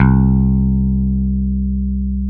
Index of /90_sSampleCDs/Roland LCDP02 Guitar and Bass/BS _Rock Bass/BS _Dan-O Bass